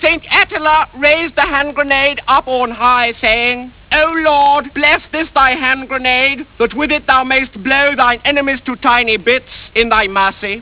This is the Bishop blessing the Holy Handgrenade